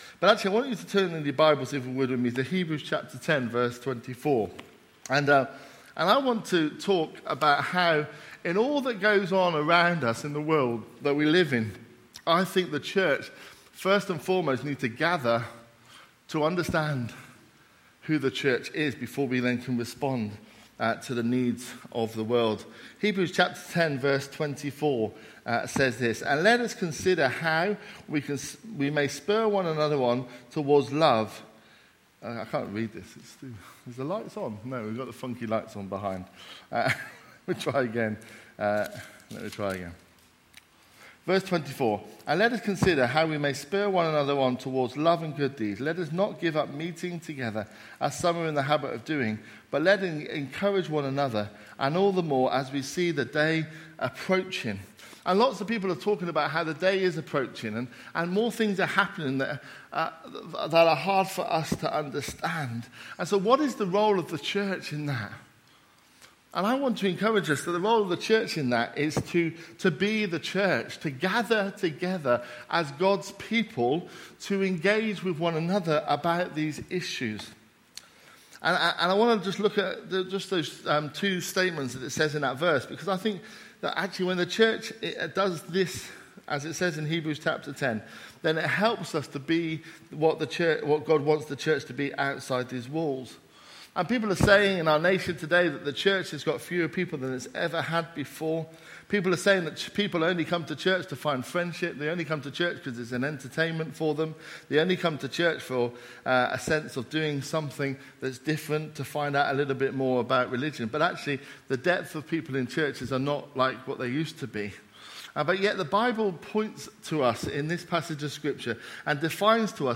A message from the series "Other."